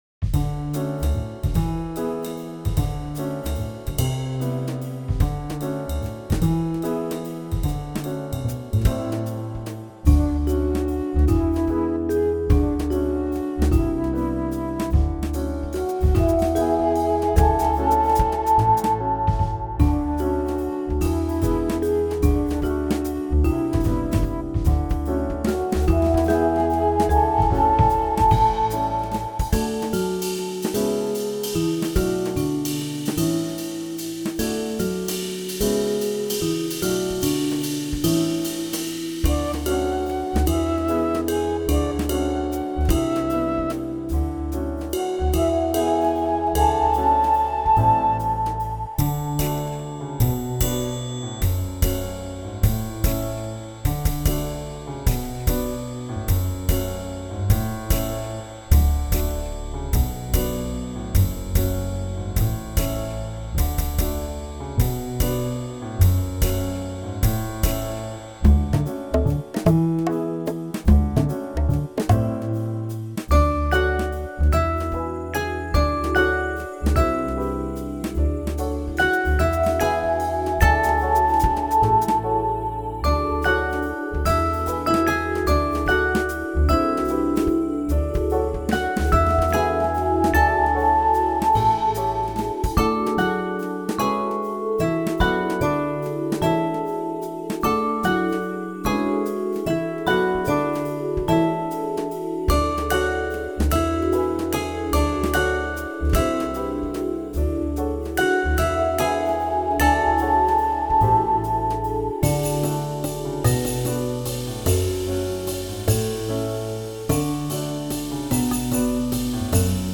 A relaxed, contemplative, jazzy song.